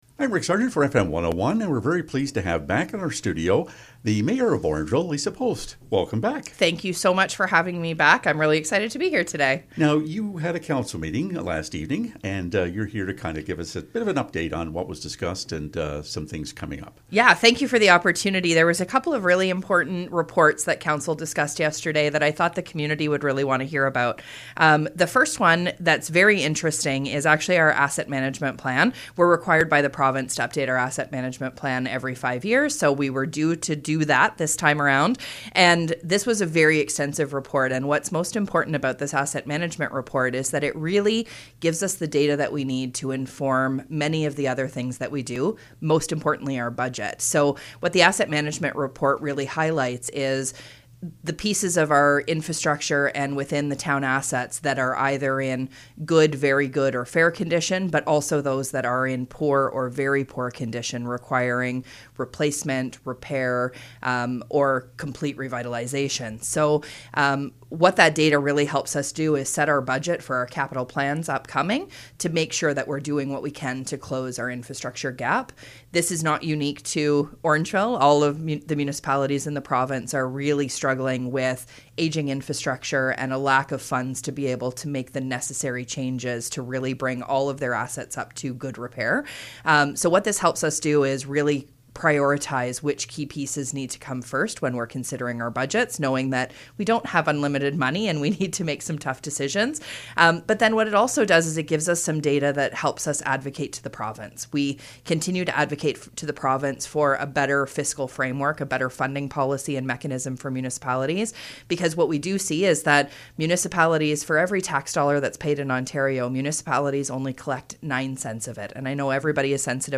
Orangeville Mayor Lisa post provides update from July 14th Council Meeting